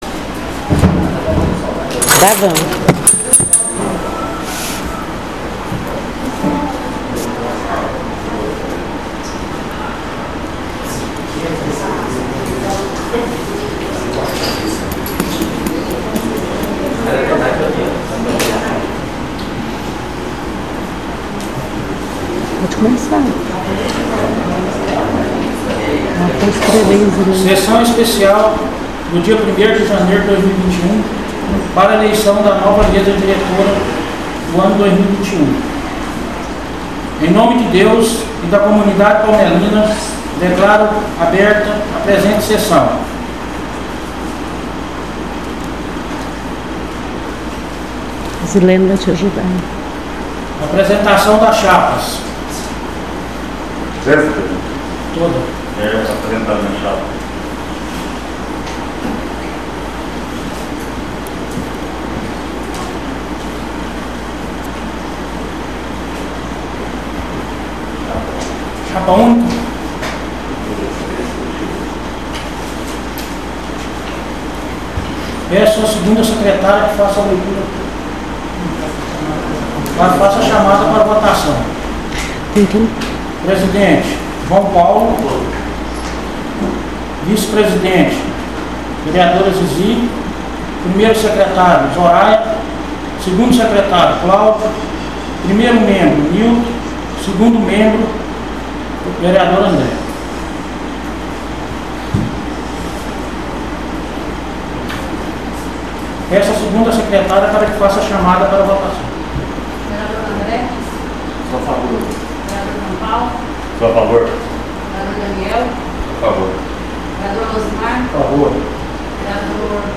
ELEIÇÃO DA MESA DIRETORA 01/01/2021